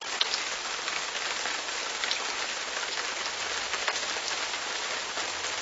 RainPorch.wav